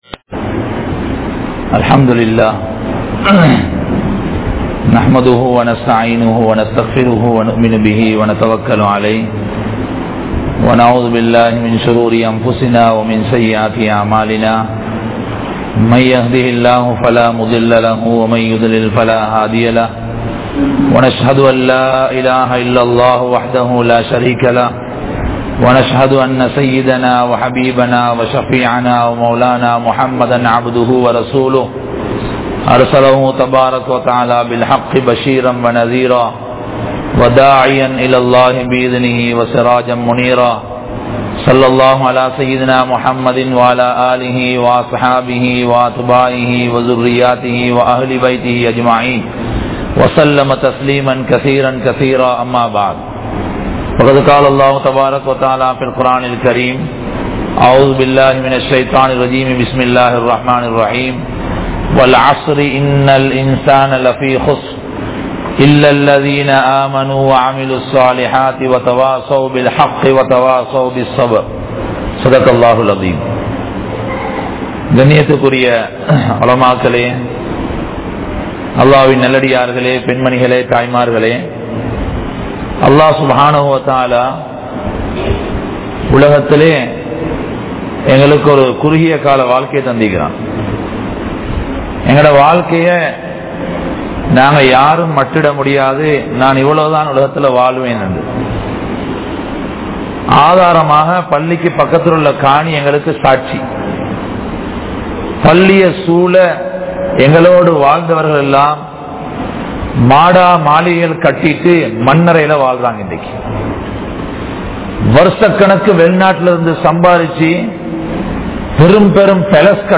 Pillaihalukku Paavaththai Palakkum Petroarhal (பிள்ளைகளுக்கு பாவத்தை பழக்கும் பெற்றோர்கள்) | Audio Bayans | All Ceylon Muslim Youth Community | Addalaichenai
Grand Jumua Masjitth